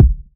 • Subby Urban Bass Drum One Shot B Key 426.wav
Royality free steel kick drum tuned to the B note. Loudest frequency: 113Hz
subby-urban-bass-drum-one-shot-b-key-426-KFp.wav